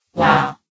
CitadelStationBot df15bbe0f0 [MIRROR] New & Fixed AI VOX Sound Files ( #6003 ) ...
wow.ogg